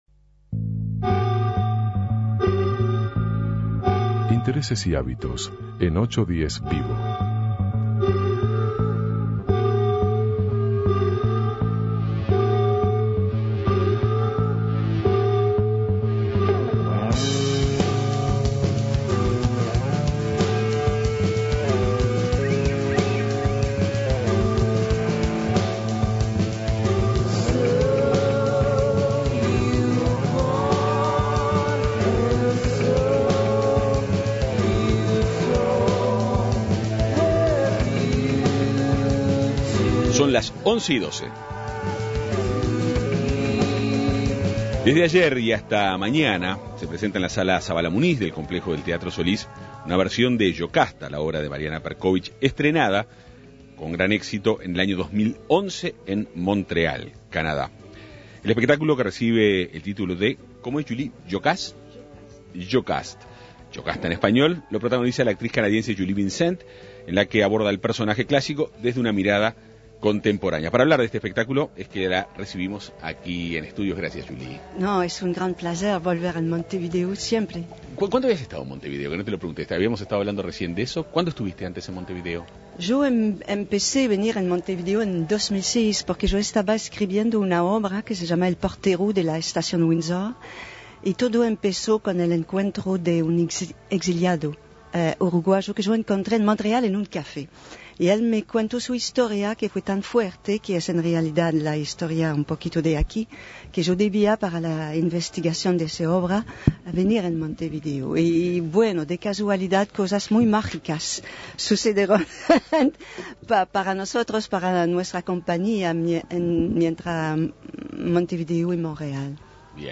810 VIVO Avances, Tendencia y Actualidad conversó con la actriz sobre el espectáculo.